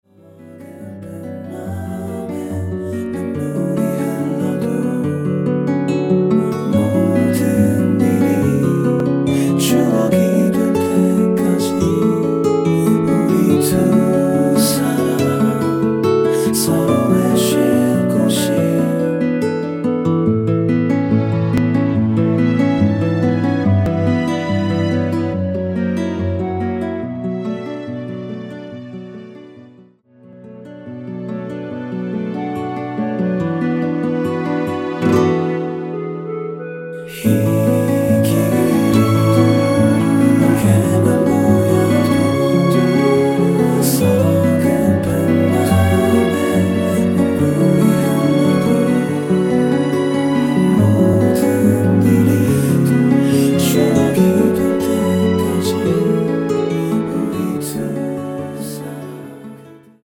1절후 바로 후렴으로 진행 됩니다.(본문의 가사 참조)
원키 2절 삭제한 멜로디와 코러스 포함된 MR입니다.(미리듣기 확인)
앞부분30초, 뒷부분30초씩 편집해서 올려 드리고 있습니다.